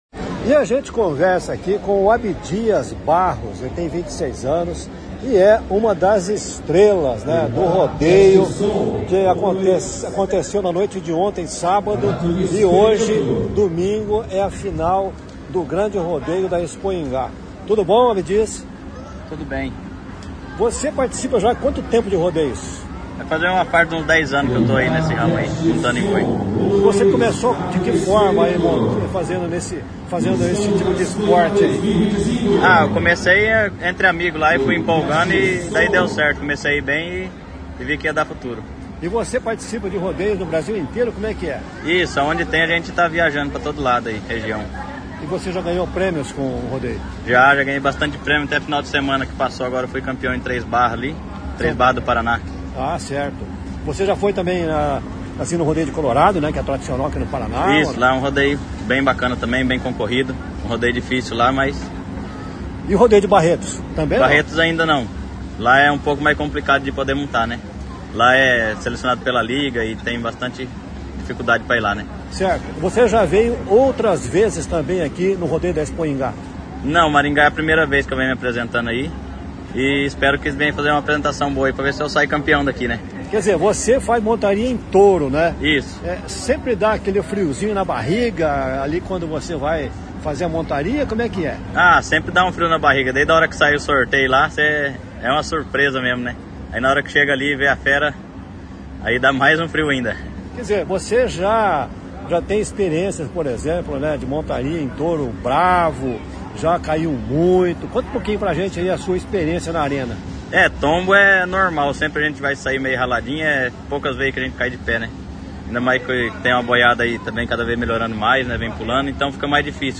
Expoingá 2025